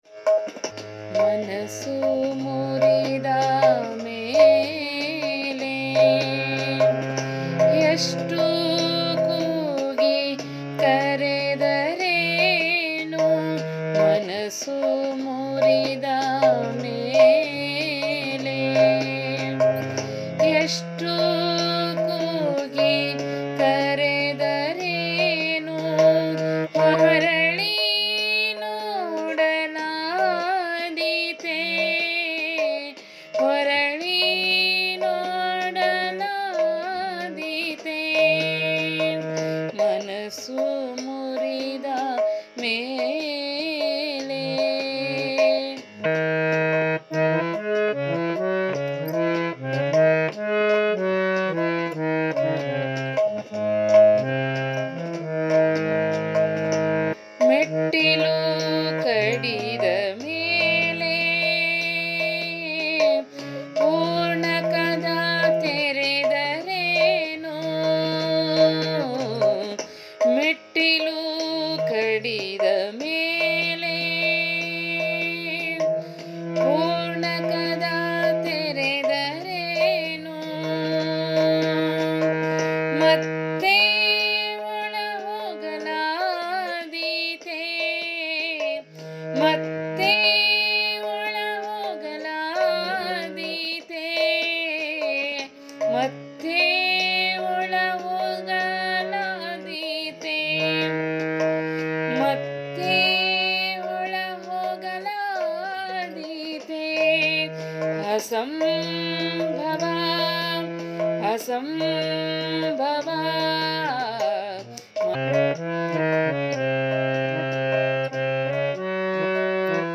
ಅಸಂಭವ, ರಾಗ ಸಂಯೋಜನೆ-ಗಾಯನ